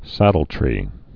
(sădl-trē)